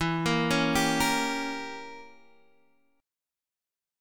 Gdim/E chord